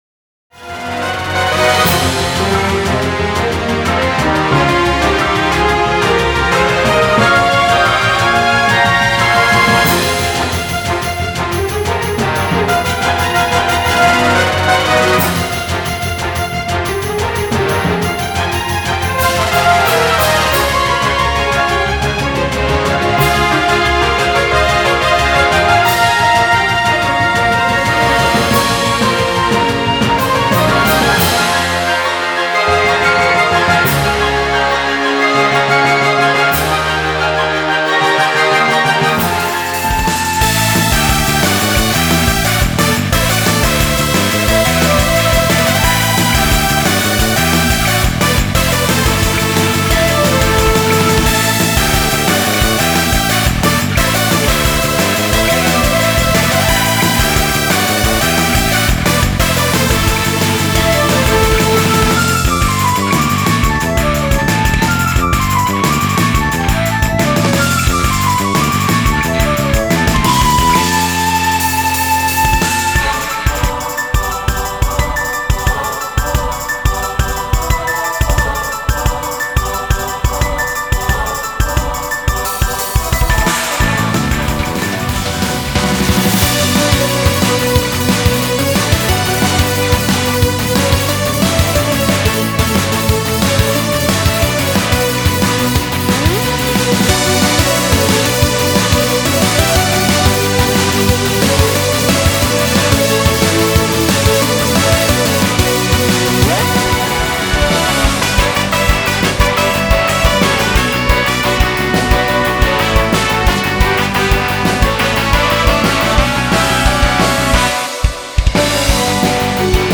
丸ごと１枚、バトル曲尽くし！！
最初から最後まで「作業妨害BGM」として走り続ける、最高に盛り上がれる１枚が誕生！
原曲を重視しつつ、現代にパワーアップされたアレンジで蘇ります！